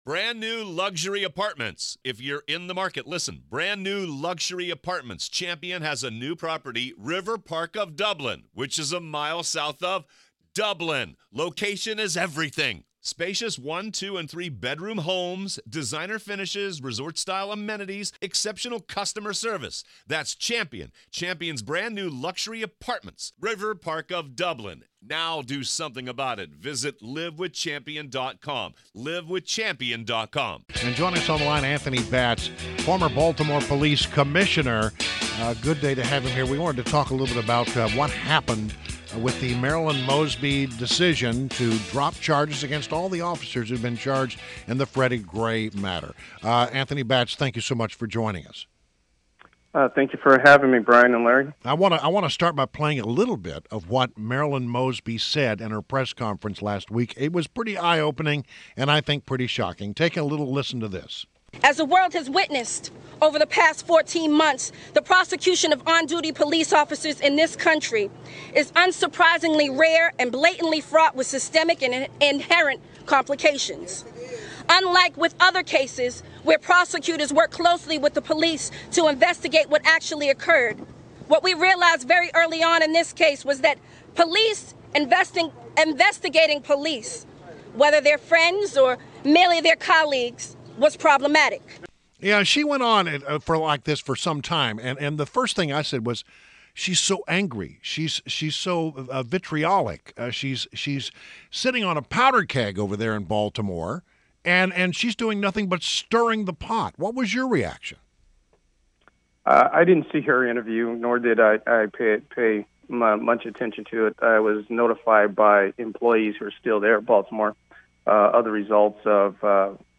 WMAL Interview - Anthony Batts - 8.1.16